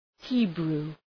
Προφορά
{‘hi:bru:}